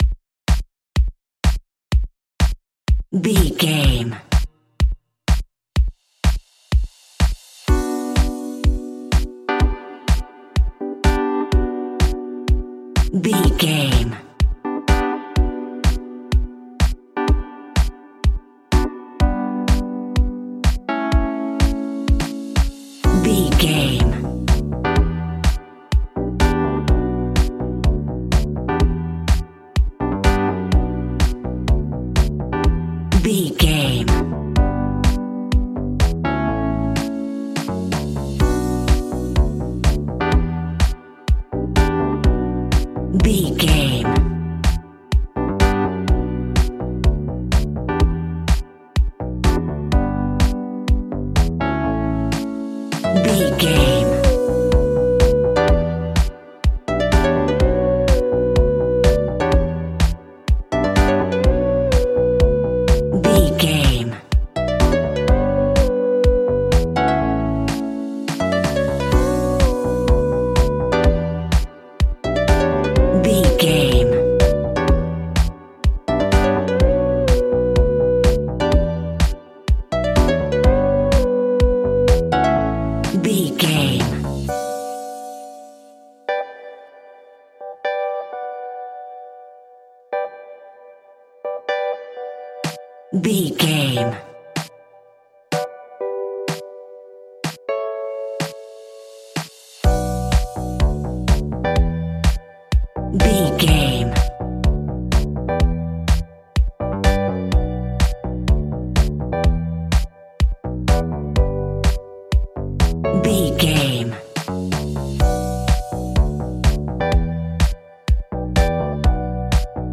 Aeolian/Minor
energetic
hypnotic
drum machine
electric piano
bass guitar
funky house
deep house
nu disco
synth